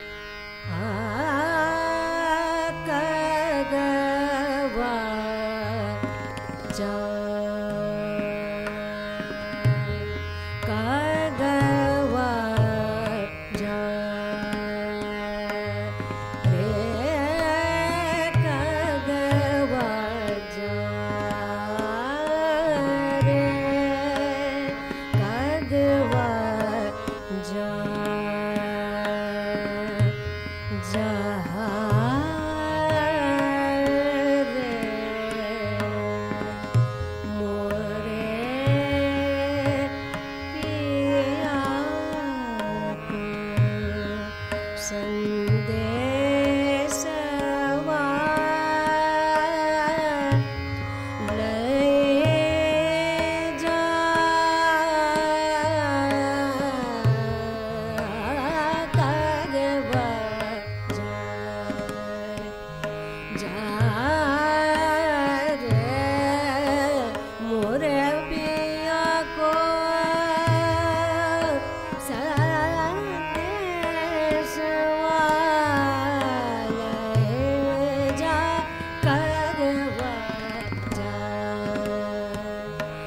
Morning melody